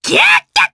Lakrak-Vox-Laugh_jp.wav